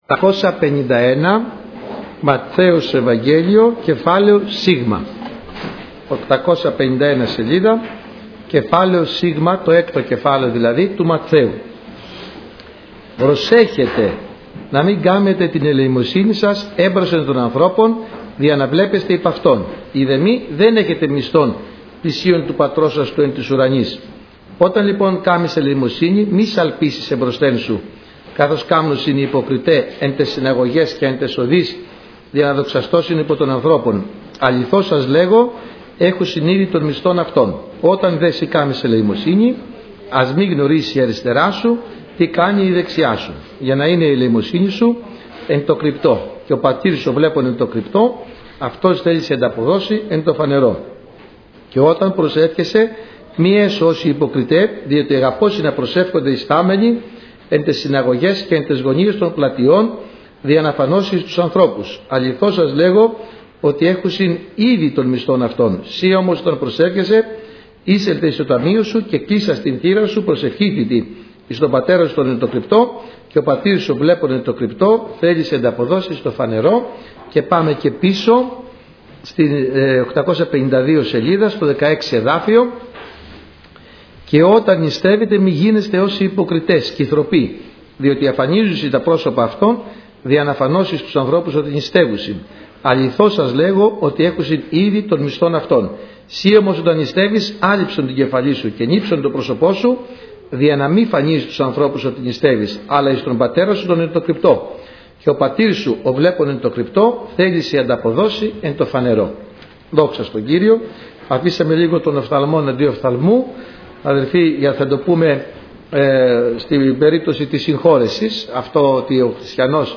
ΑΡΧΕΙΟ ΚΗΡΥΓΜΑΤΩΝ